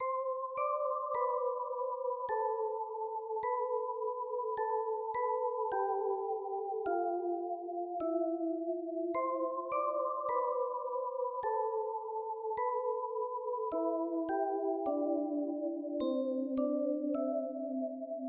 Tag: 105 bpm Rap Loops Bells Loops 3.08 MB wav Key : C FL Studio